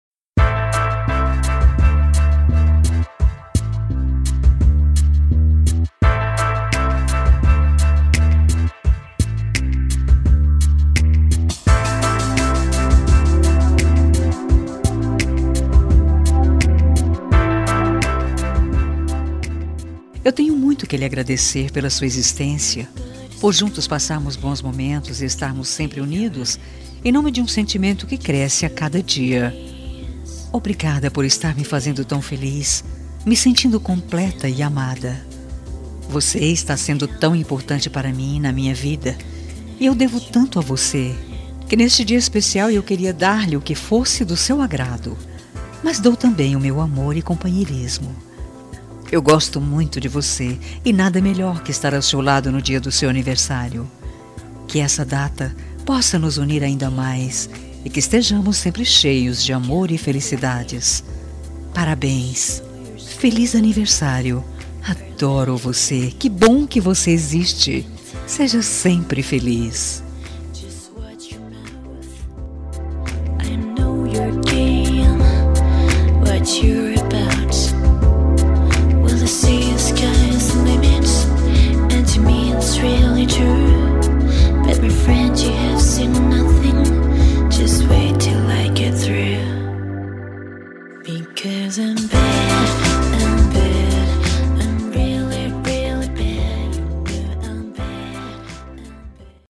Telemensagem de Aniversário Romântico – Voz Feminina – Cód: 202119 – Suave